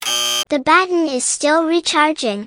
stuncharge.wav